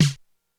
Index of /90_sSampleCDs/300 Drum Machines/Korg DSS-1/Drums01/04
Snare_95.wav